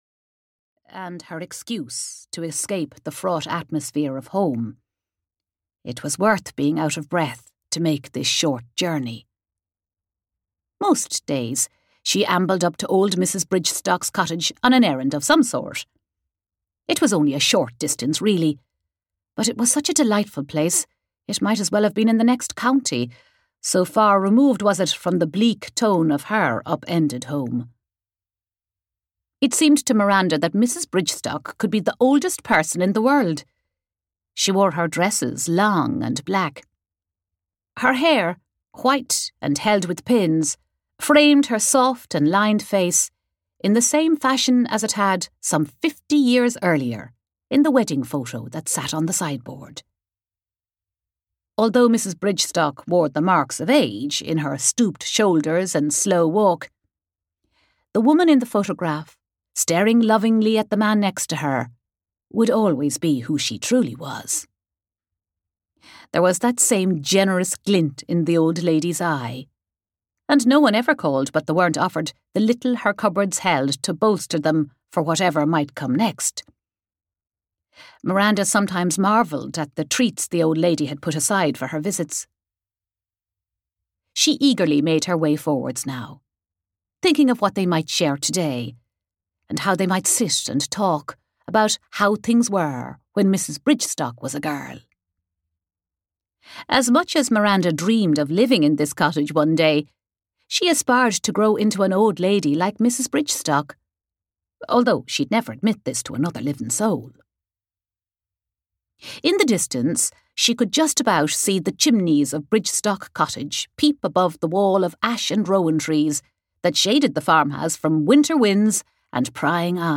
The Place We Call Home (EN) audiokniha
Ukázka z knihy